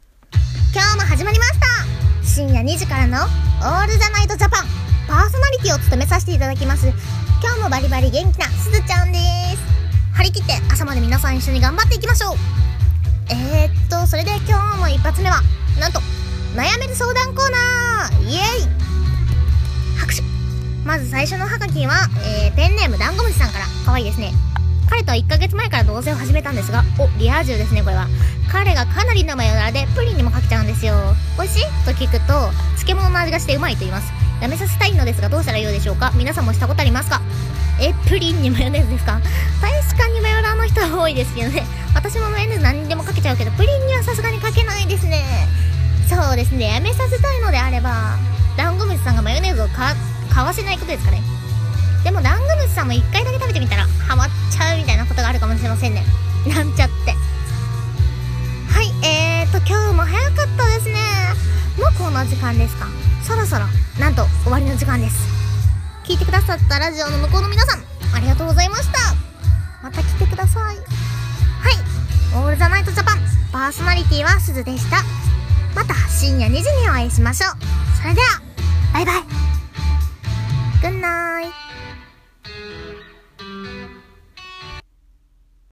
【オール・ザ・ナイト・JAPAN。】 【1人声劇台本】